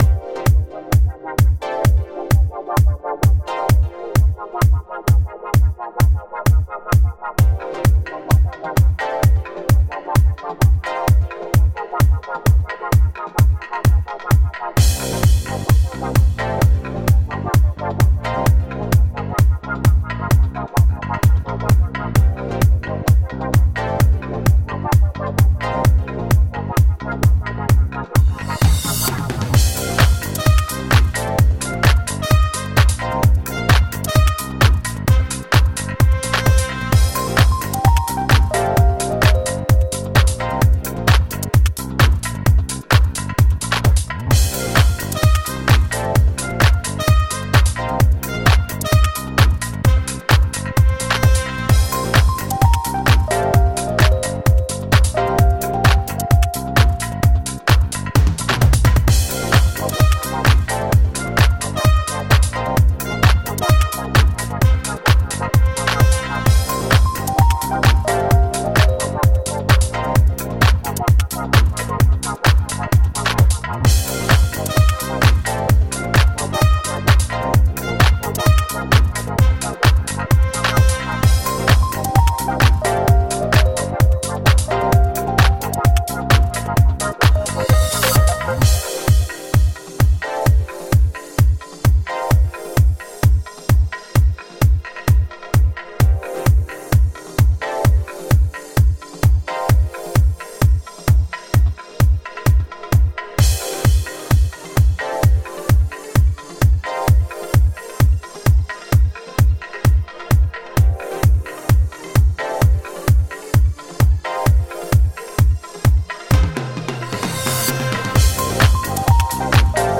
Tagged as: Electronica, Pop, Chillout, Happy Hour